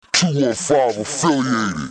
Hip-Hop Vocals Samples